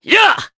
Kasel-Vox_Attack3_jp.wav